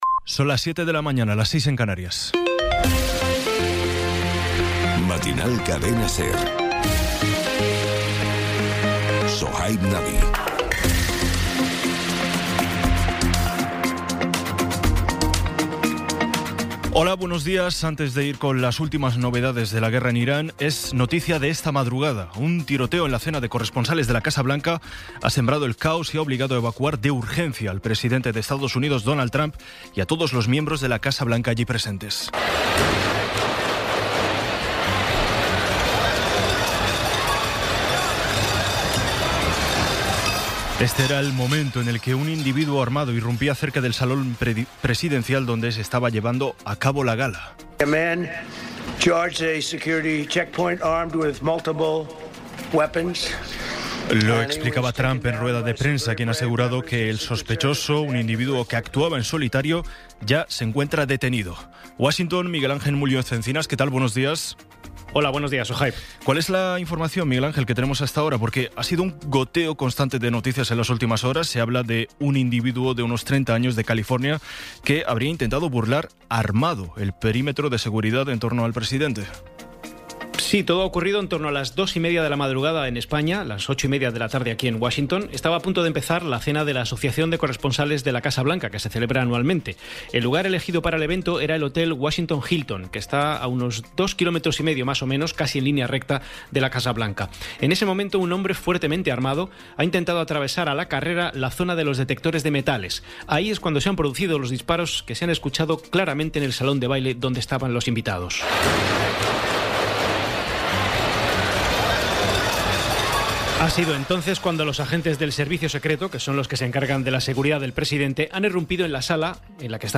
Resumen informativo con las noticias más destacadas del 26 de abril de 2026 a las siete de la mañana.